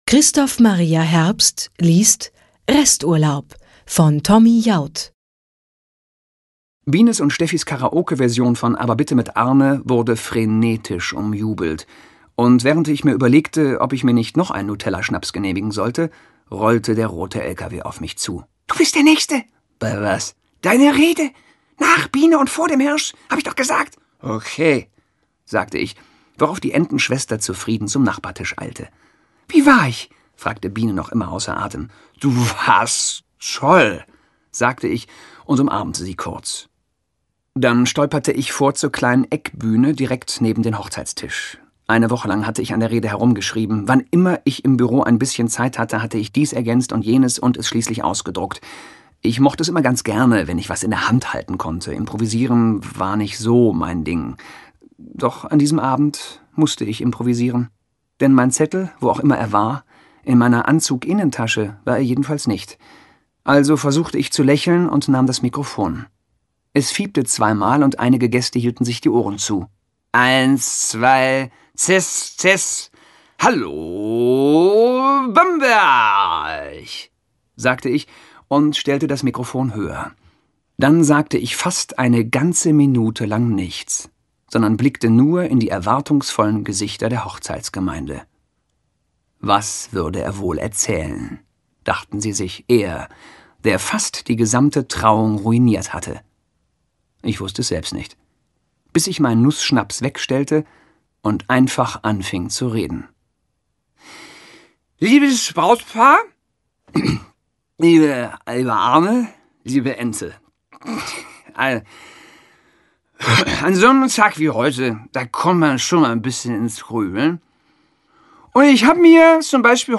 Christoph Maria Herbst liest Resturlaub, Teil 1